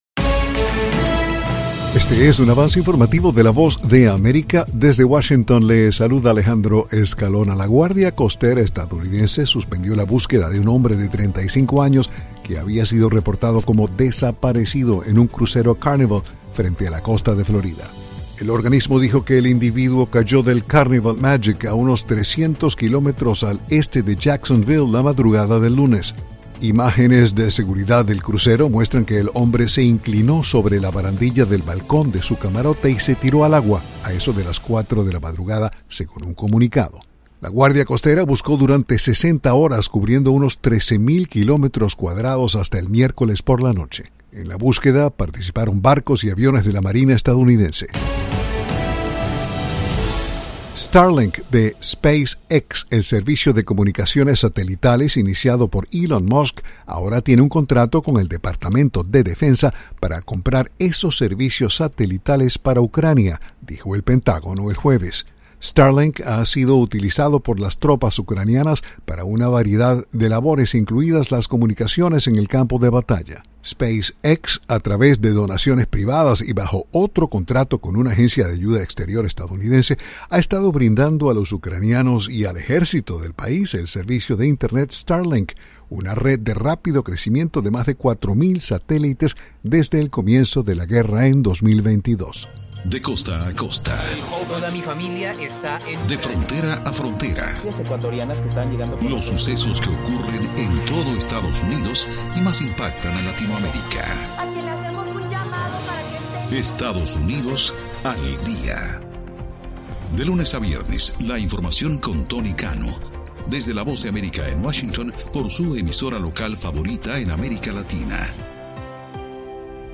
Este es un avance informativo presentado por la Voz de América desde Washington.